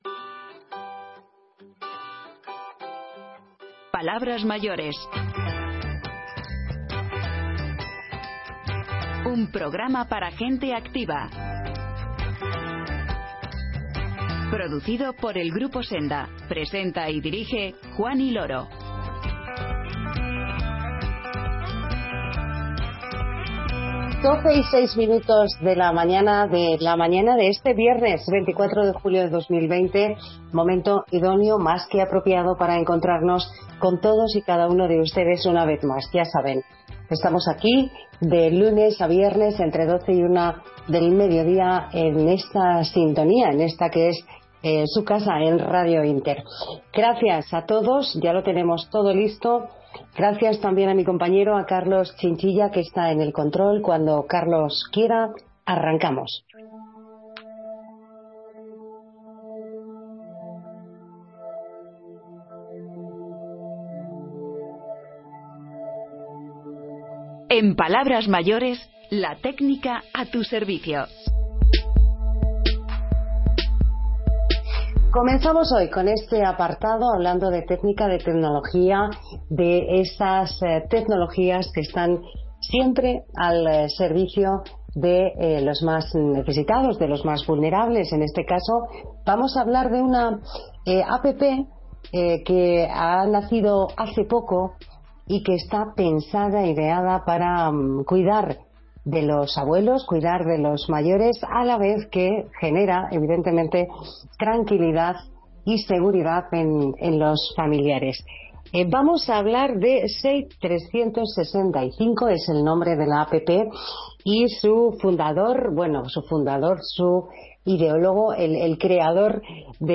En tiempo de recuerdos, escuchamos algunas de las canciones del verano más representativa de los años 50, 60 y 70.